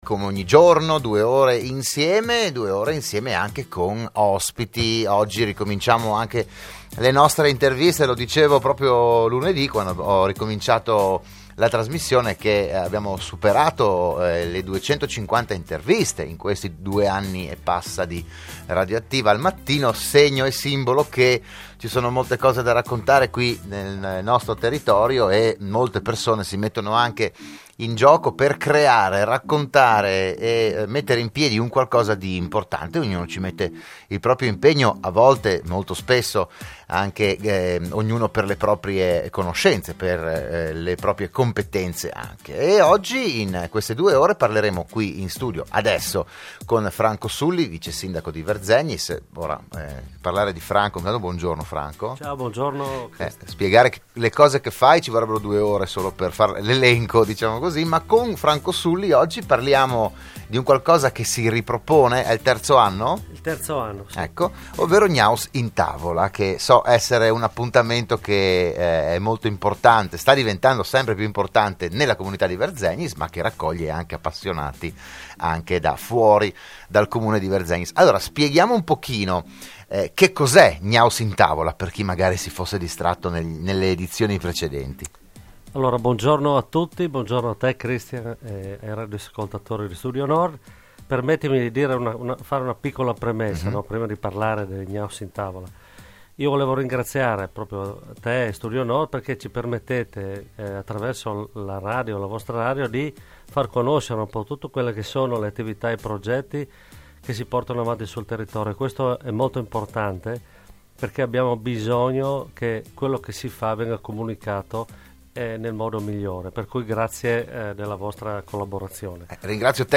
Anche quest'anno l'amministrazione comunale propone un percorso culinario dedicato alle rape. Il PODCAST dell'intervento del vicesindaco Franco Sulli a Radio Studio Nord